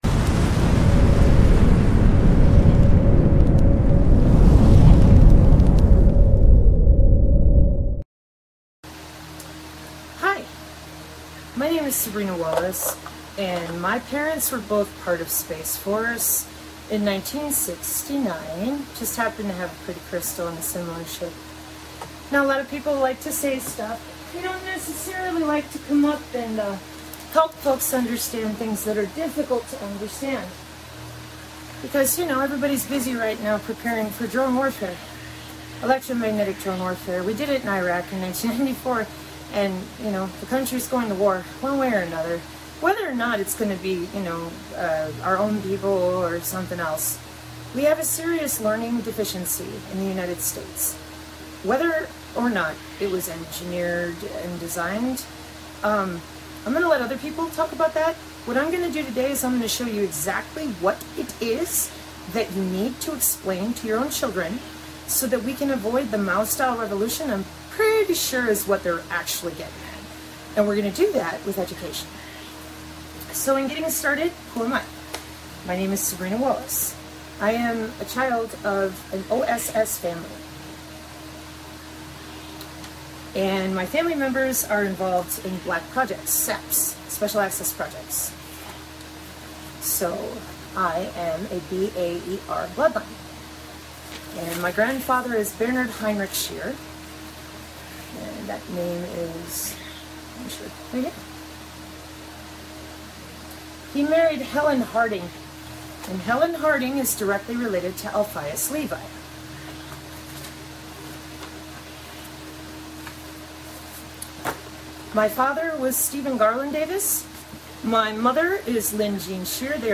Technical Presentation